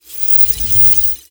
failsound.ogg